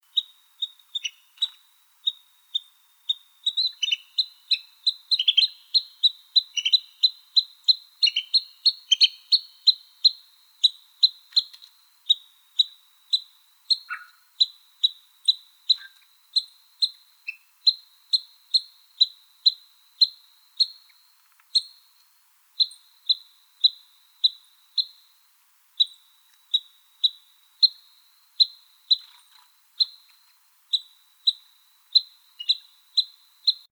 pygmynuthatch.wav